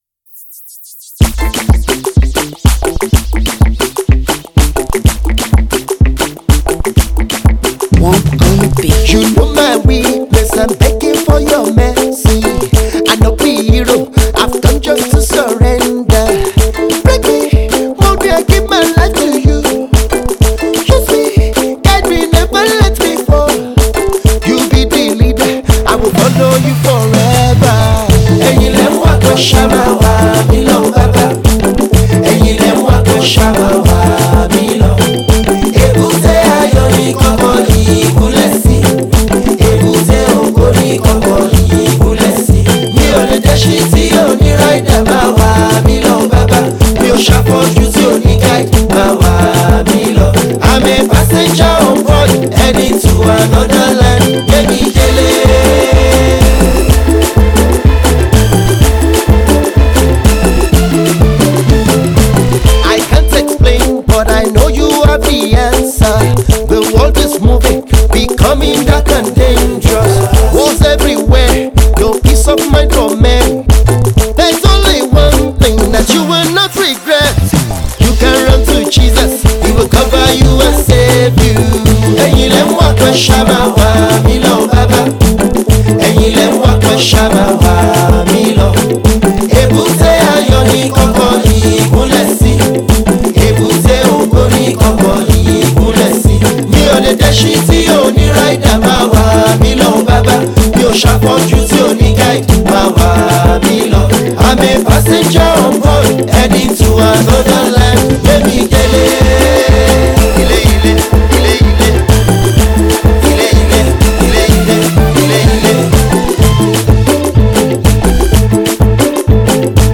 gospel
vocalist